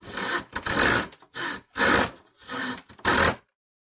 in_copingsaw_sawing_03_hpx
Coping saw cuts various pieces of wood. Tools, Hand Wood, Sawing Saw, Coping